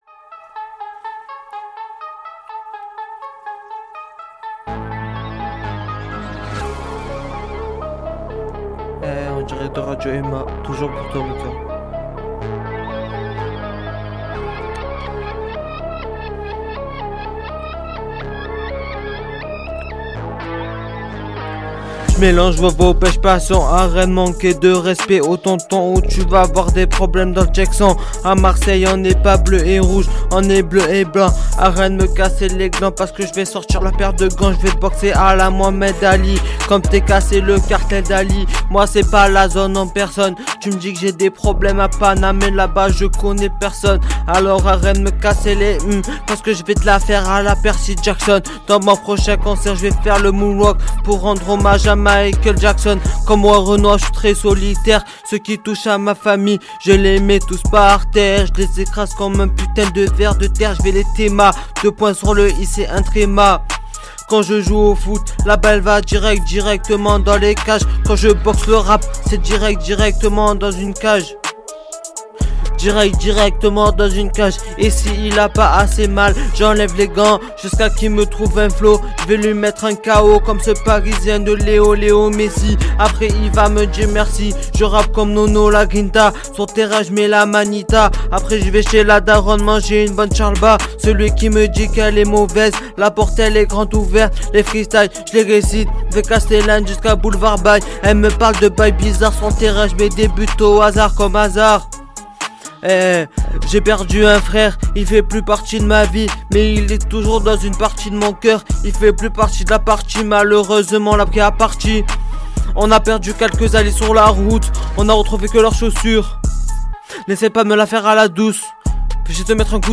nous livre un Freestyle à la plume inspirée!